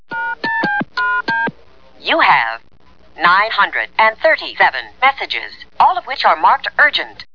COMPUTER VOICE FILES